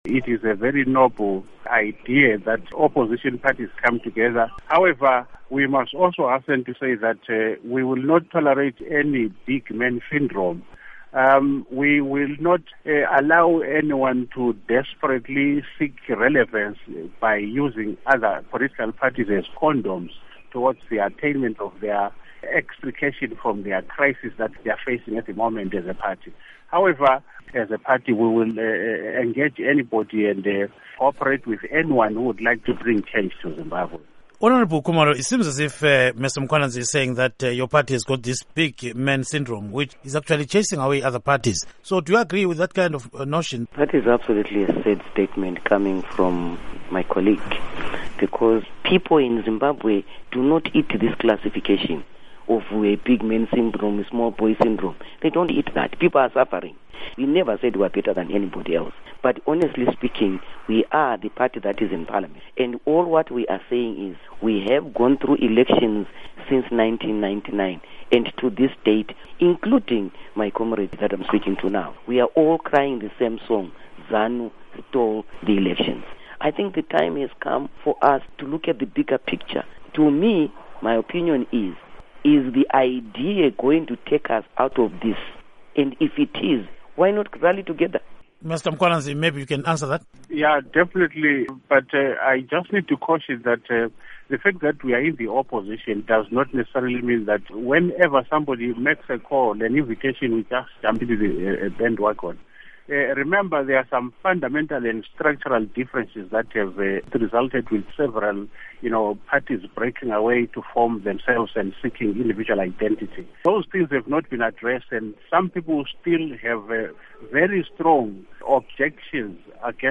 Electoral Reforms Debate: Interview